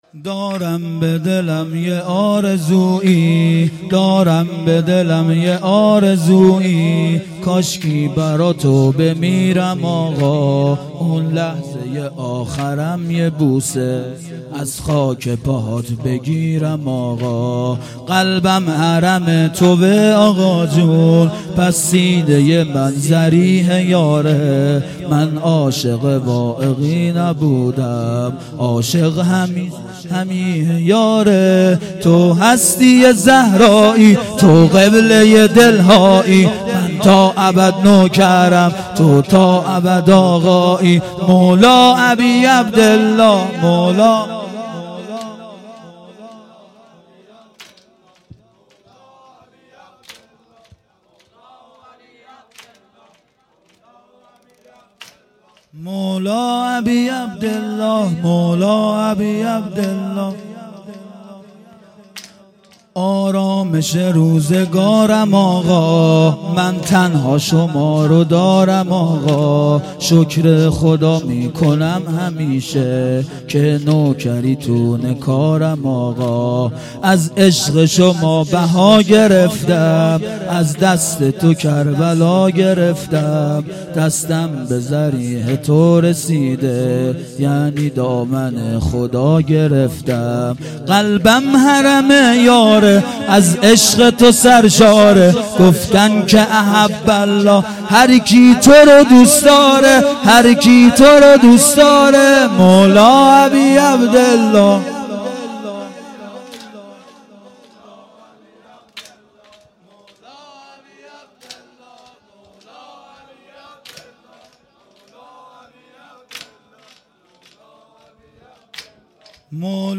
اصوات مراسم سیاهپوشان ودهه اول محرم۹۷هییت شباب الحسین